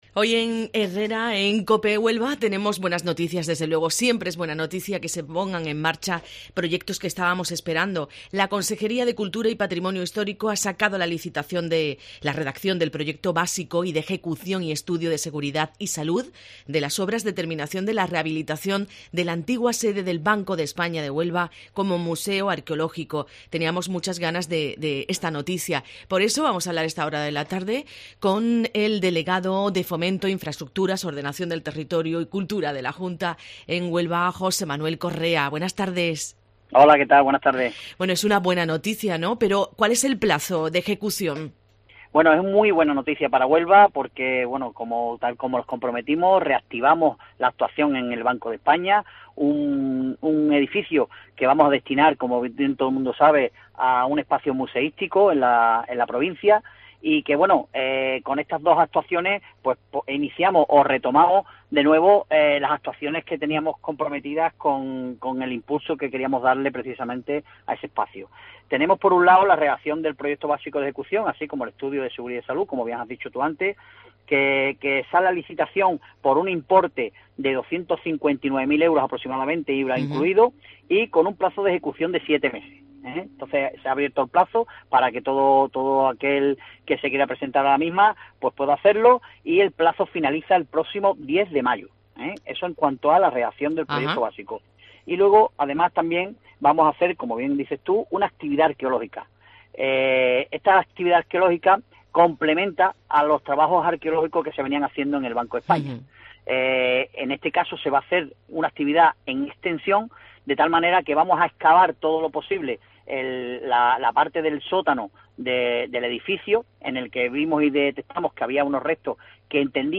En Herrera en COPE Huelva hemos hablado con Jose Manuel Correa, delegado territorial de Fomento, Infraestructuras, Ordenación del Territorio, Cultura y Patrimonio Histórico de Huelva.